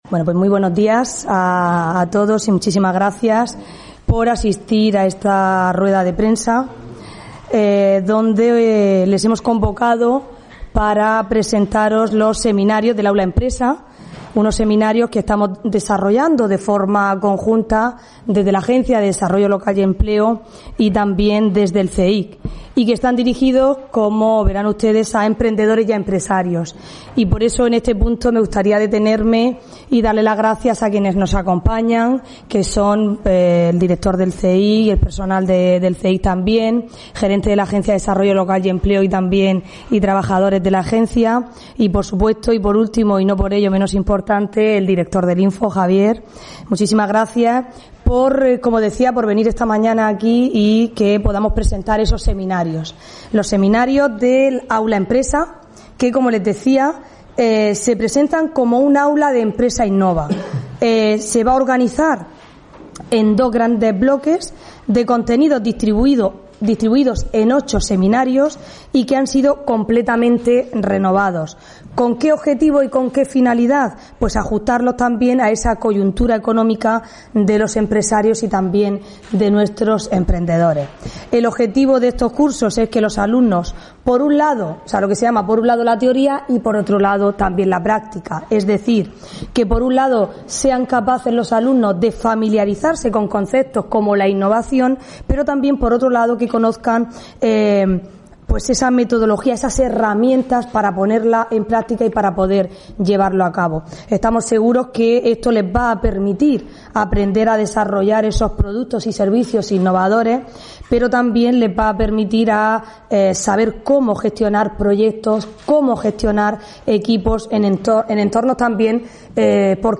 Audio: Rueda de prensa presentaci�n del ciclo de seminarios Aula Empresa Innova que organizan la ADLE y el INFO (MP3 - 3,22 MB)